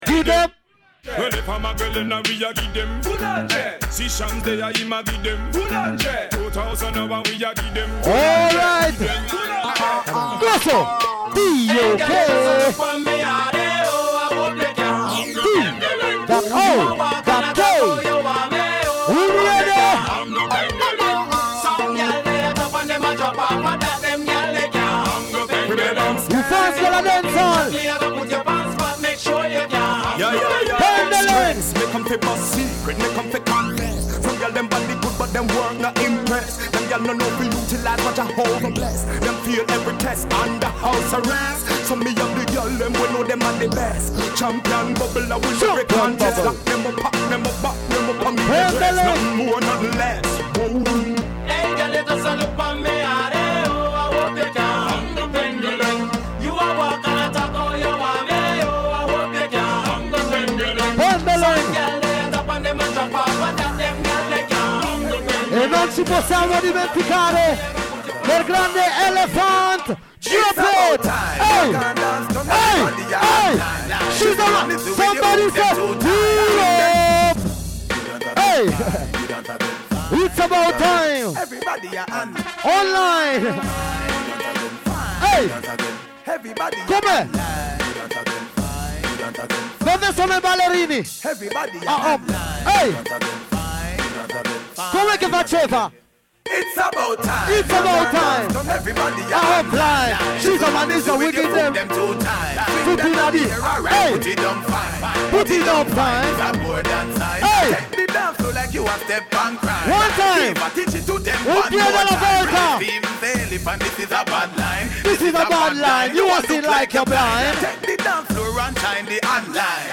SWING A LING Dj Set by GOLDEN BASS SOUND / I-TAL SOUND MILANO / SOLID VIBES REGGAE CREW
il meglio della Reggae e della Dancehall music anni ’80/’90/’00
il tutto amplificato da un VERO SOUND SYSTEM AUTOCOSTRUITO!!